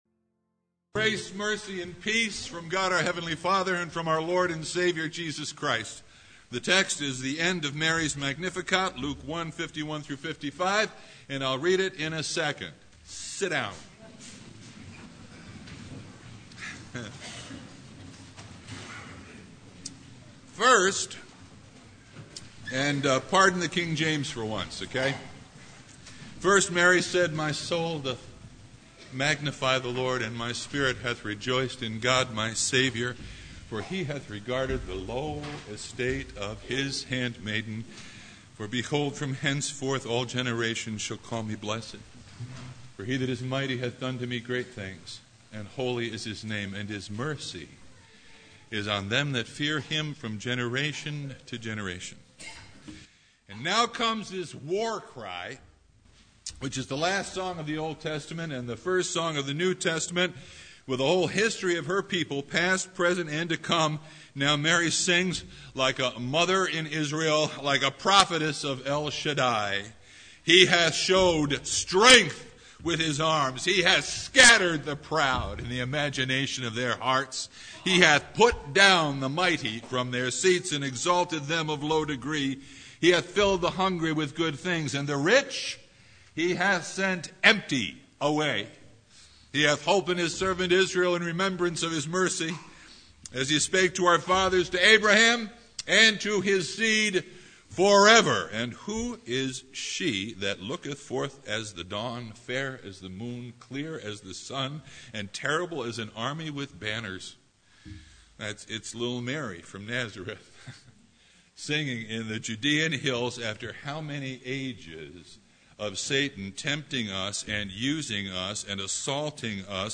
Passage: Luke 1:51-55 Service Type: Christmas Day
Sermon Only